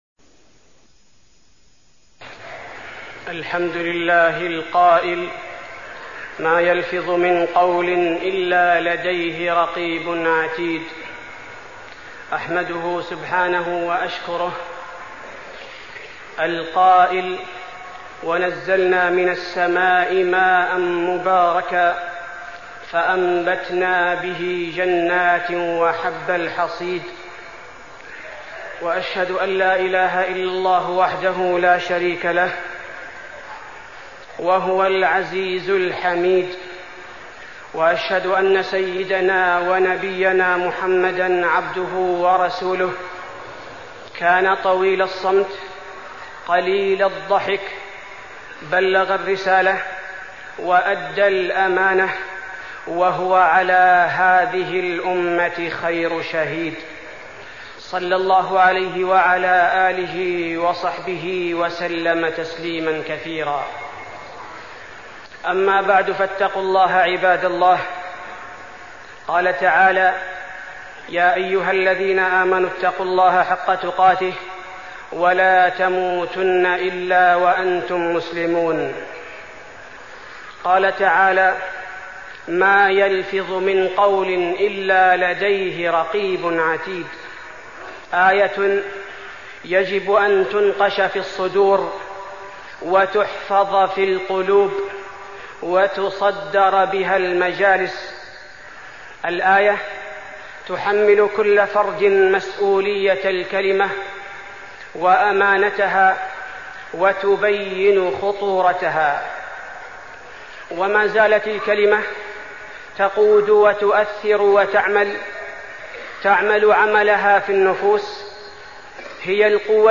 تاريخ النشر ٢٣ رجب ١٤١٦ هـ المكان: المسجد النبوي الشيخ: فضيلة الشيخ عبدالباري الثبيتي فضيلة الشيخ عبدالباري الثبيتي أمانة الكلمة